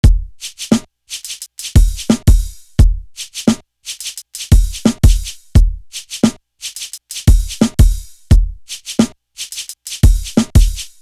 Still Feel Me Drum.wav